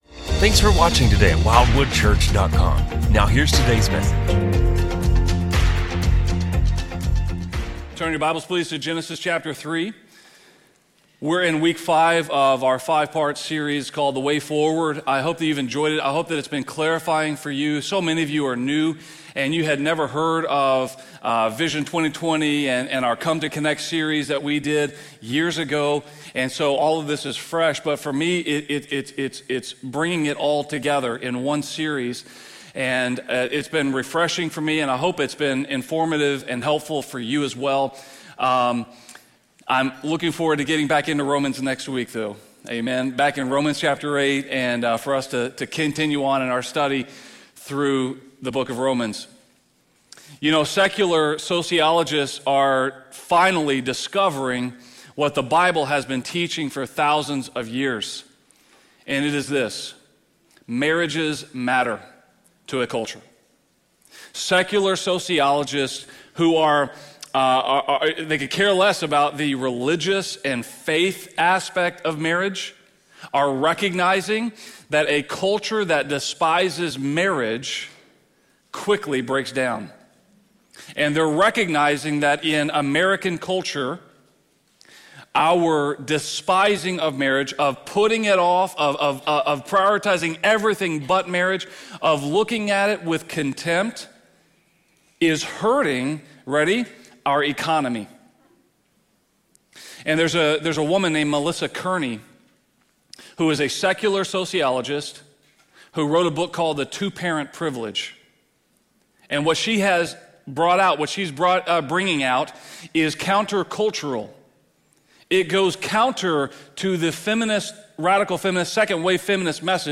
A message from the series "Vision 20/20."